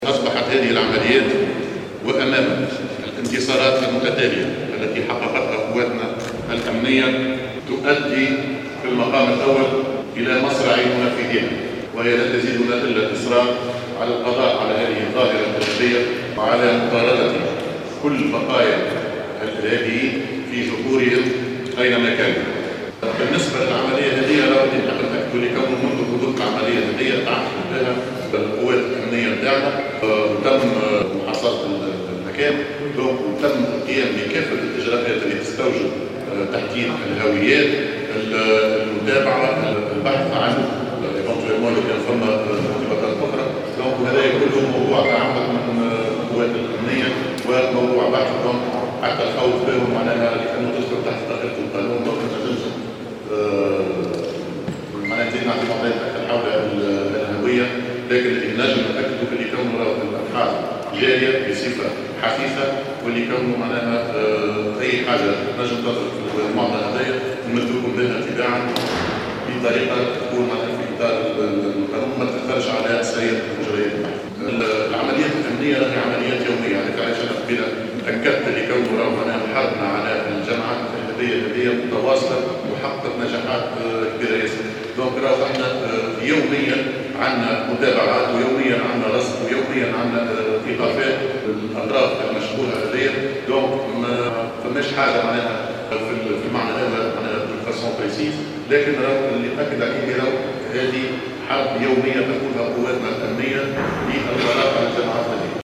كما أكد وزير الداخلية ،خلال ندوة صحفية عقدتها الوزارة، أن الأبحاث جارية لتحديد هويات الإرهابيين الذين استهدفا دورية أمنية قارة في محيط السفارة الأمريكية.